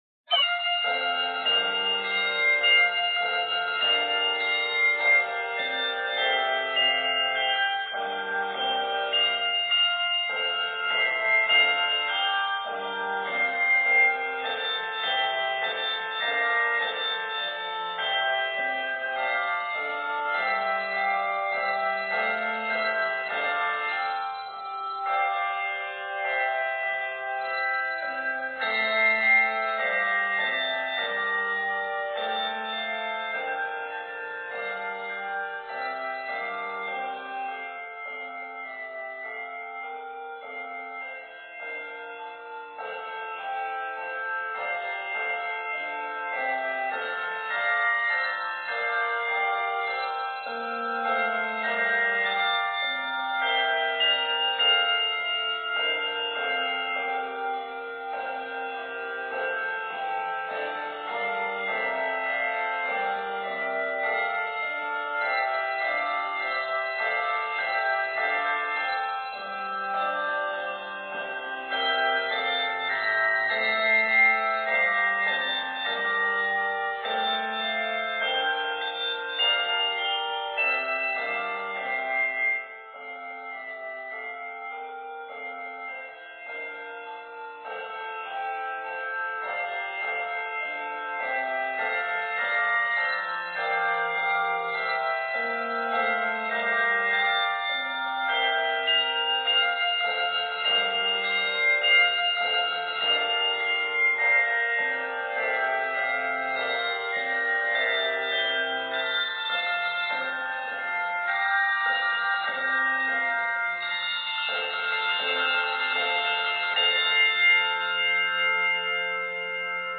played by 3, 4 and 5-octave ensembles
handbell or handchime choir